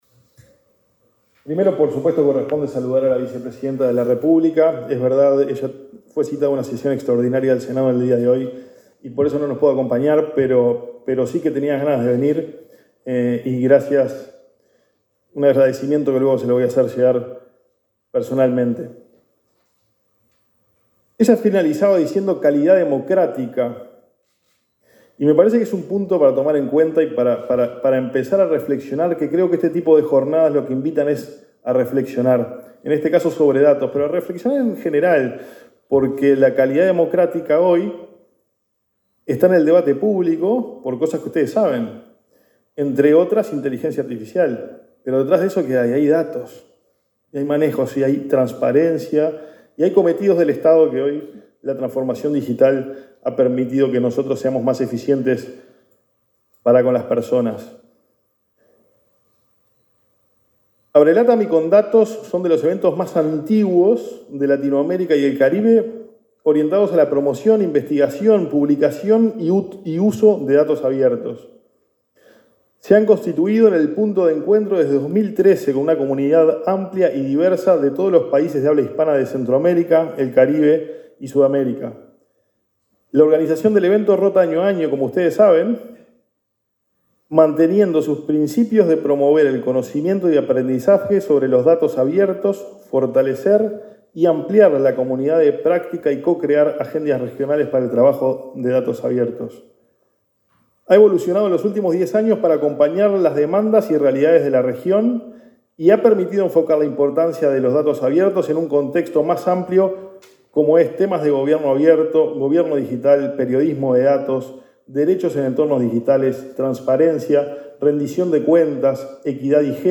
Declaraciones del director ejecutivo de Agesic, Hebert Paguas
Declaraciones del director ejecutivo de Agesic, Hebert Paguas 31/10/2023 Compartir Facebook X Copiar enlace WhatsApp LinkedIn Este martes 31, el director ejecutivo de la Agencia de Gobierno Electrónico y Sociedad de la Información (Agesic), Hebert Paguas, participó en Montevideo, de la apertura de ABRELATAM, la conferencia regional de datos abiertos para América Latina y el Caribe.